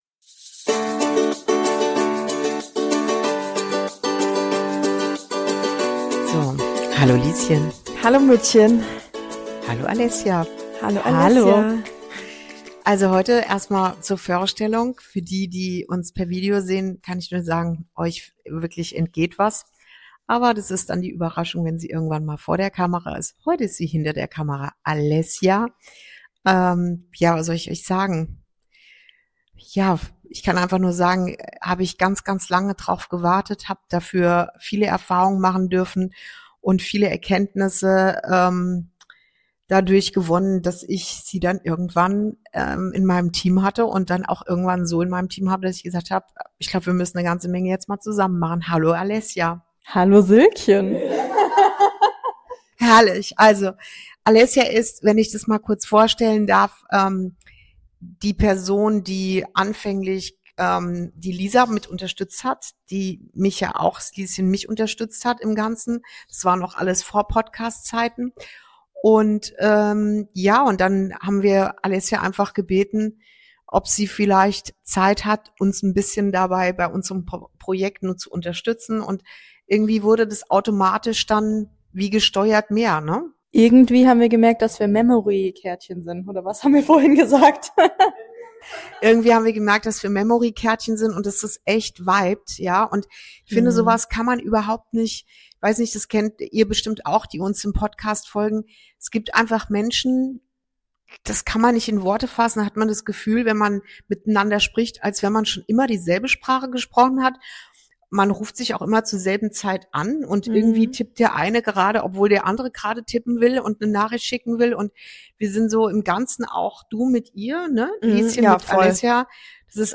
Folge 13: Wenn du nicht suchst – findet es dich. ~ Inside Out - Ein Gespräch zwischen Mutter und Tochter Podcast